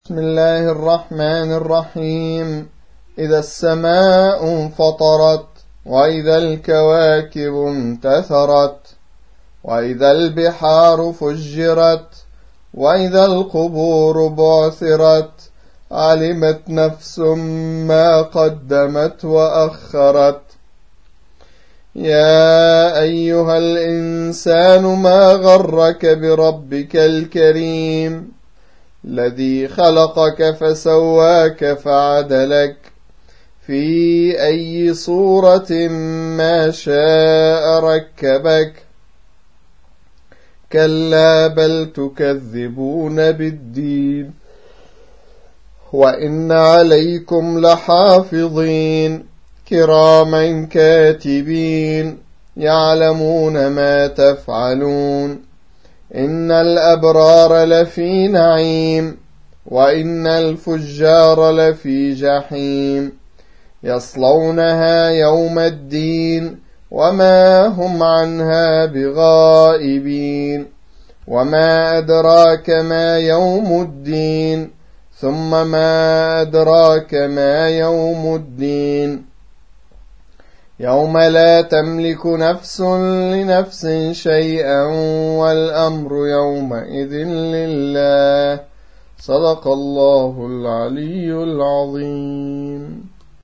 82. سورة الانفطار / القارئ